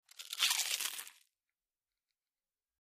Slower Bite Into Chips, X7